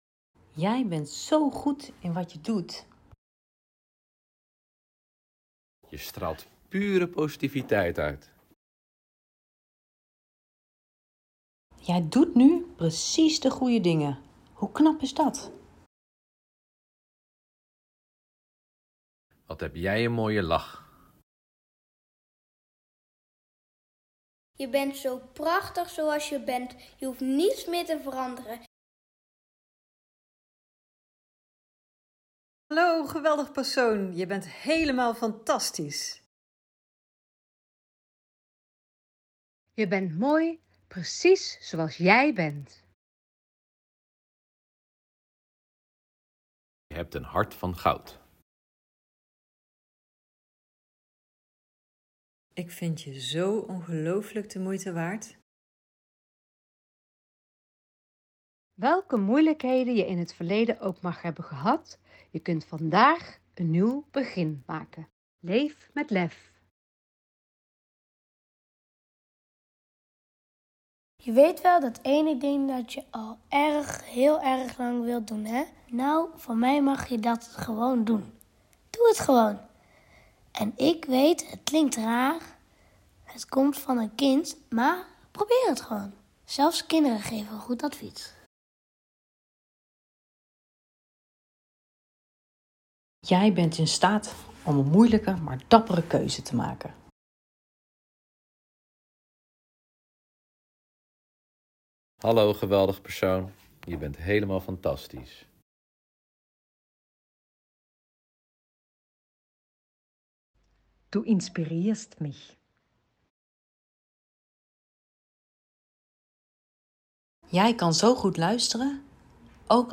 Al liggend op een strandstoel en met een koptelefoon op, krijgen ze complimenten als "Jij bent bijzonder" en "Je kàn dit; echt appeltje-eitje voor jou".